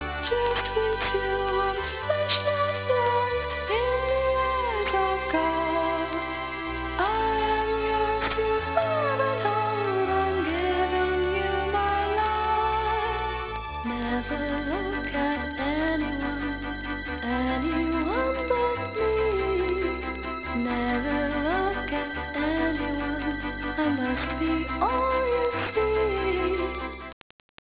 Mono excerpt